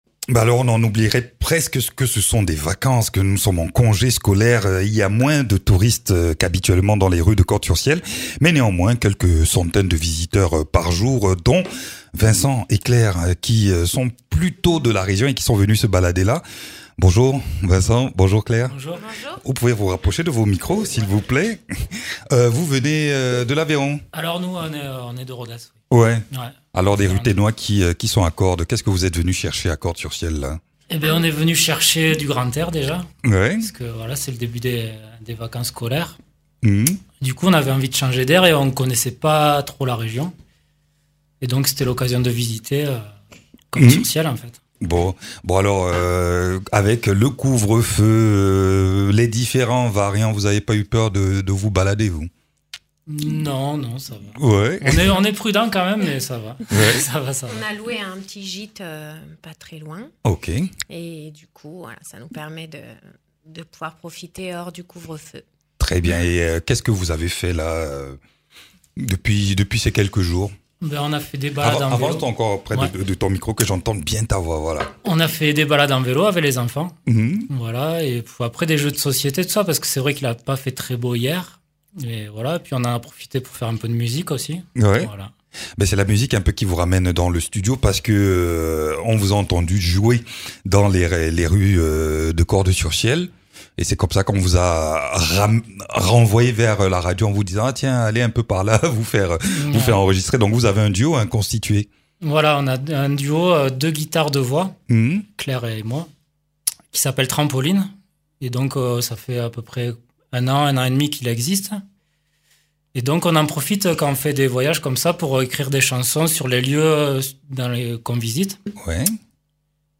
Interviews
Invité(s) : Trampoline, duo de musique basé en Aveyron